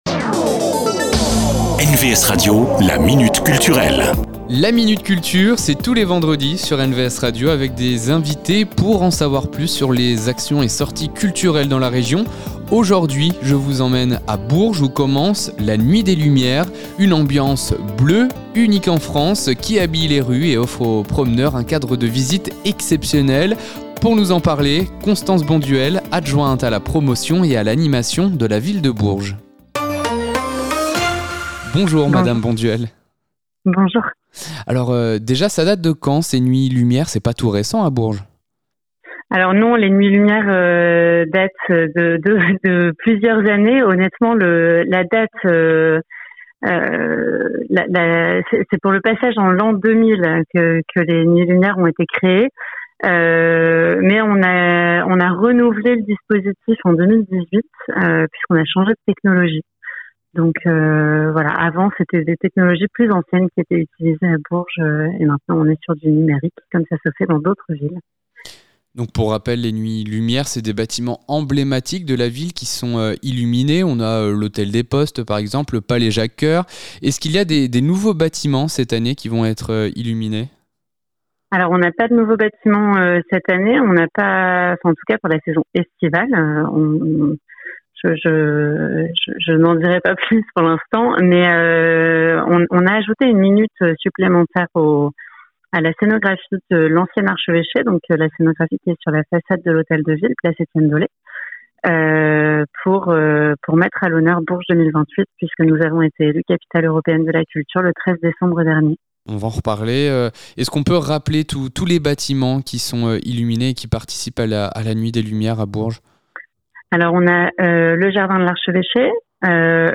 La Minute Culture, rencontre avec les acteurs culturels de la région.
Cette semaine : Constance Bonduelle, adjointe à la mairie de Bourges et en charge des Nuits Lumière.